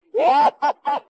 звуки животных